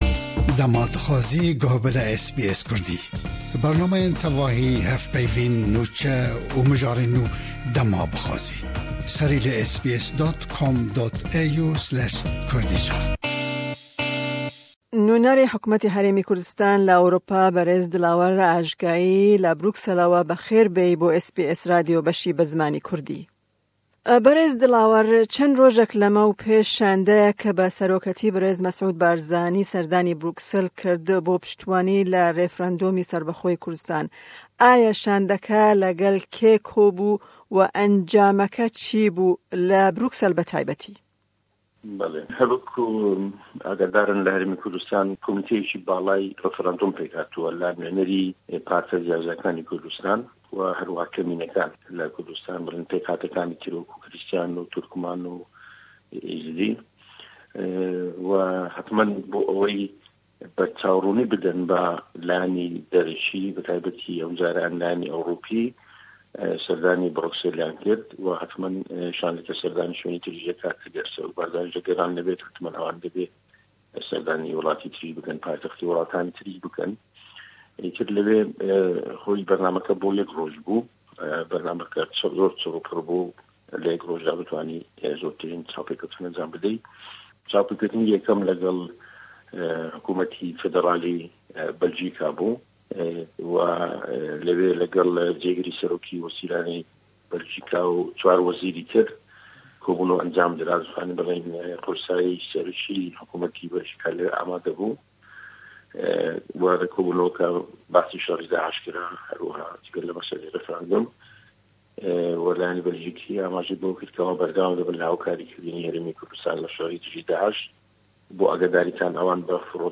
Em bi nûnerê Hukmeta Herêma Kurdistanê li Ewropa berêz Delawar Ajgeiy bo encamên sebaret bi serdana shandeya bi rêbertiya berêz M. Barzanî bo Ewropa jibo daxwaza pishtgiriya refrendomê ji welatên Ewropî û jibo danûstendinên tev Ewropa axifîn.